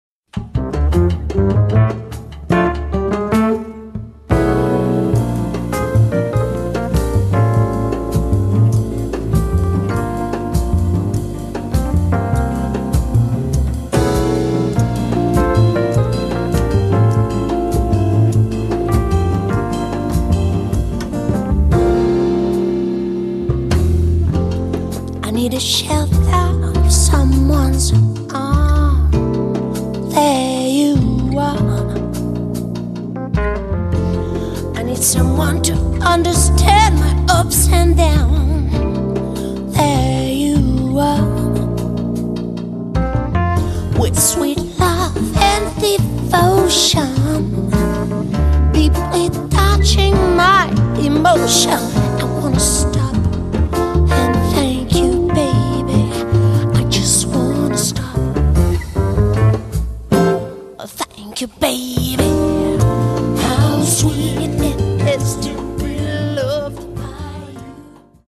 chitarre, voce
contrabbasso
batteria
piano
tromba